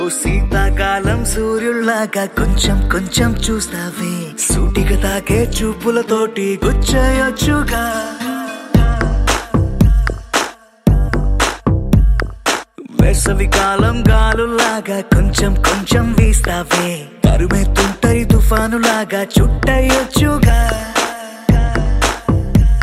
best flute ringtone download
romantic ringtone download
melody ringtone